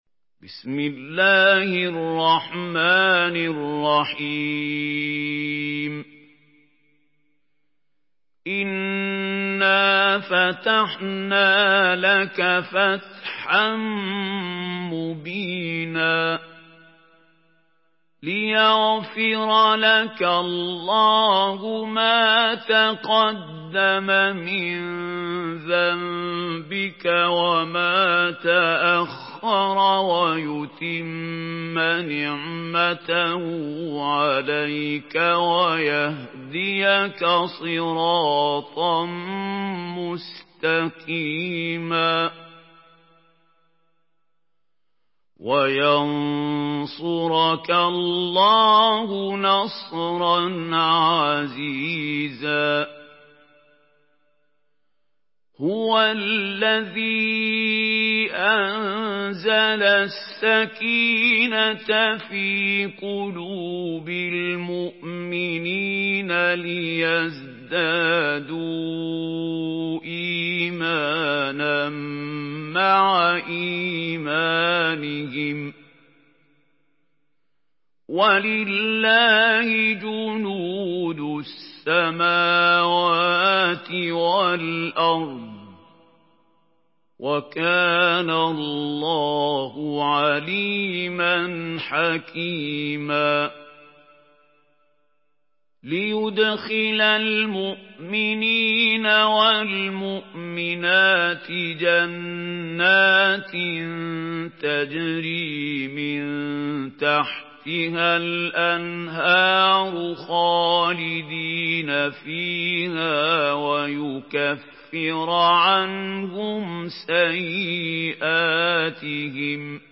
سورة الفتح MP3 بصوت محمود خليل الحصري برواية حفص
مرتل